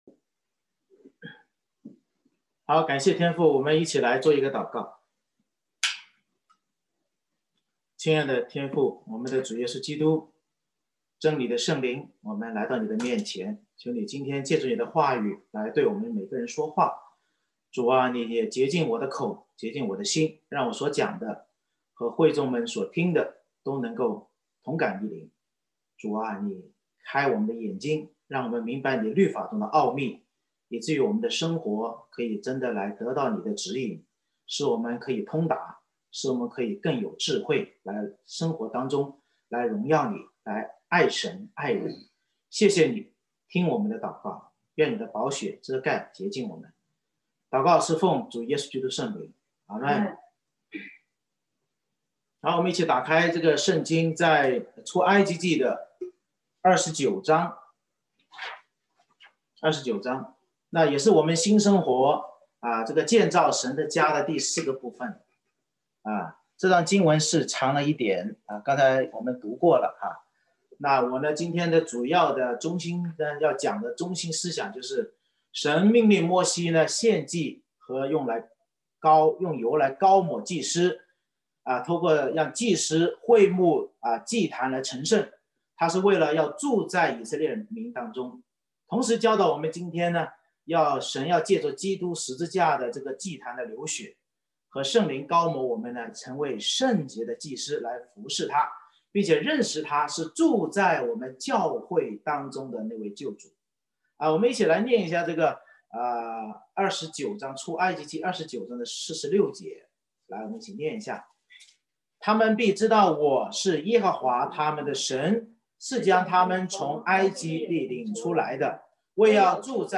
November 15, 2020 建造神的家（四）－－成圣之法和目的 Series: 《出埃及记》讲道系列 Passage: 出埃及记29章 Service Type: 主日崇拜 神命摩西借献祭与油使祭司会幕和祭坛成圣，是为使神住在以色列当中。